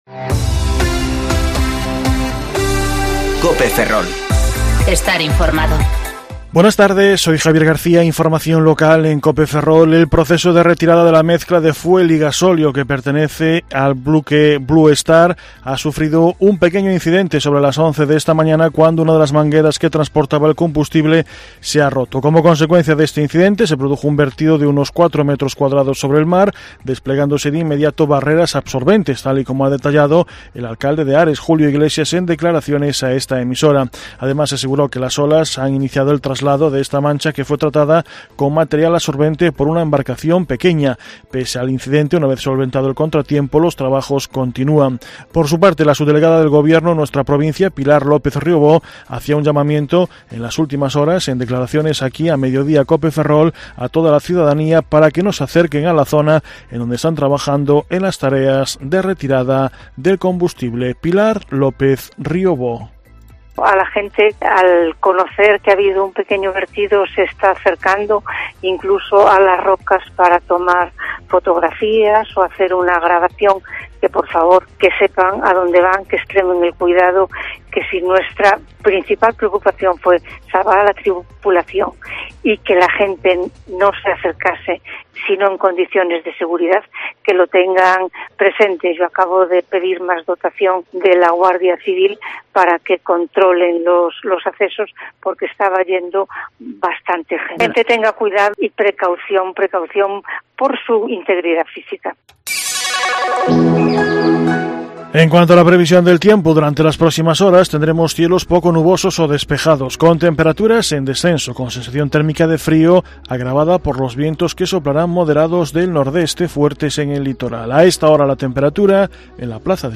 Informativo Mediodía Cope Ferrol 2/12/2019 (De 14.20 a 14.30 horas)